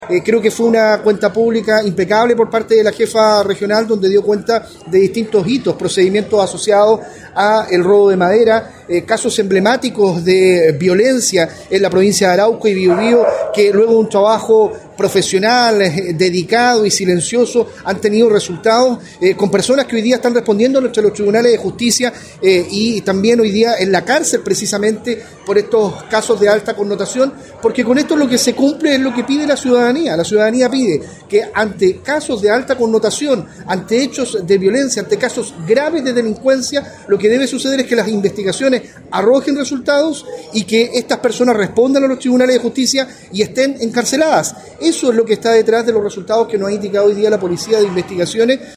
En el marco del aniversario número 92 de la institución, y con la presencia del director nacional, Eduardo Cerna, la Policía de Investigaciones en el Biobío realizó la Cuenta Pública de Gestión Policial correspondiente al último año.